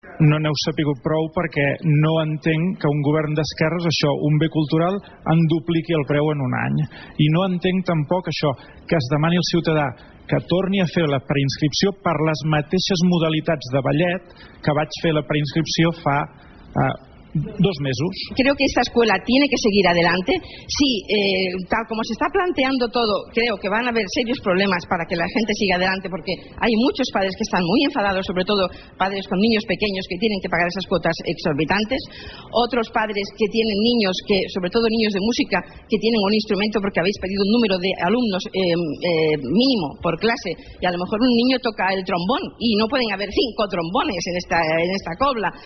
Des del primer moment els pares van mostrar el seu malestar a través de les xarxes i també a la mateixa sala de plens.